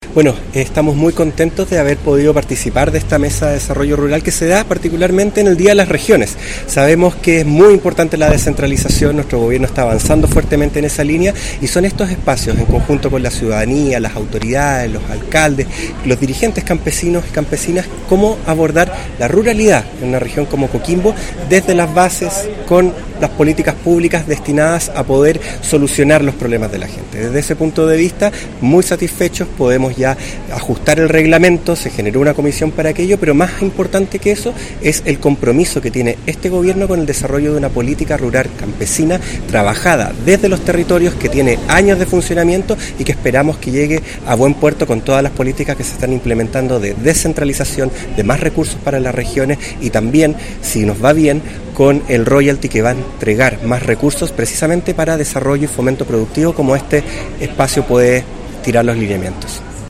En tanto el Delegado Presidencial Regional de Coquimbo, Rubén Quezada, añadió que